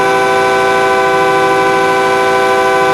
Horn Pack